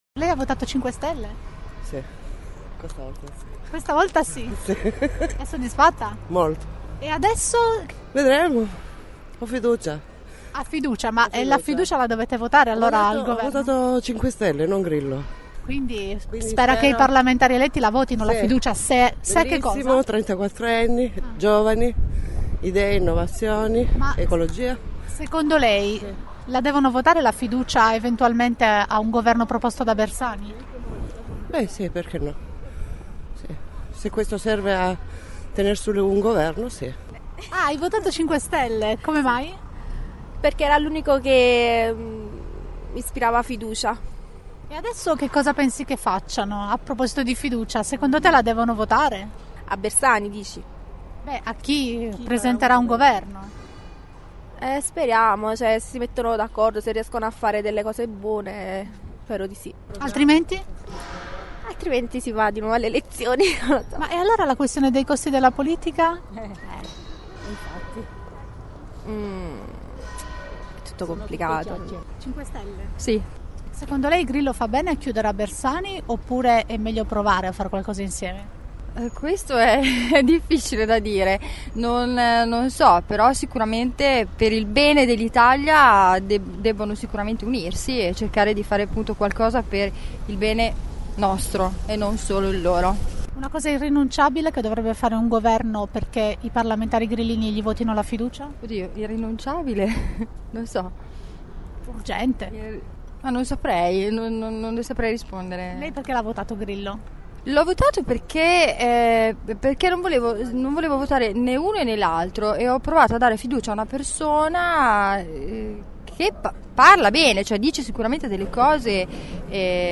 Sono quasi tutti disponibili a dare fiducia a un eventuale governo Bersani gli elettori del movimento che abbiamo intervistato questa mattina in piazza Maggiore.